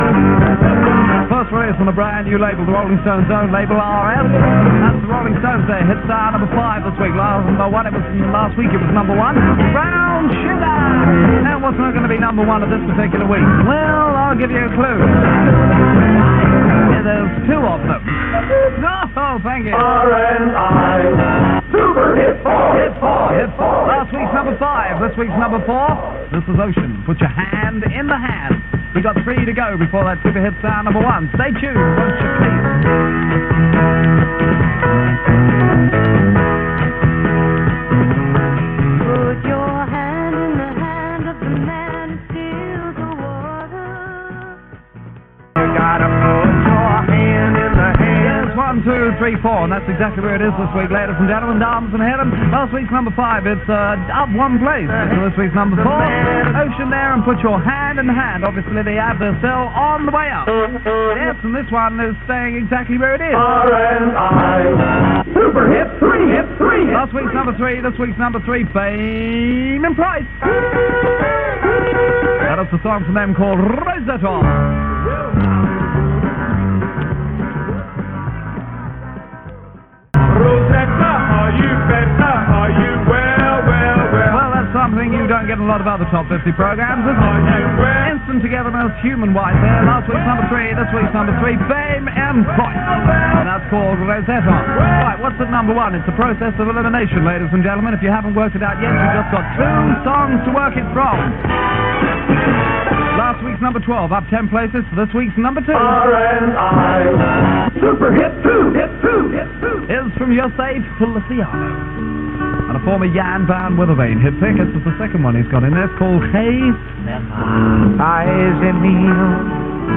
the top positions on the ‘Super Hit 50’ show on Radio Northsea International